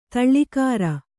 ♪ taḷḷikāra